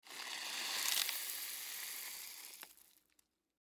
На этой странице собраны разнообразные звуки, связанные с пищевой солью: хруст кристаллов, шум пересыпания, звук растворения в воде и другие.
Сыпем побольше соли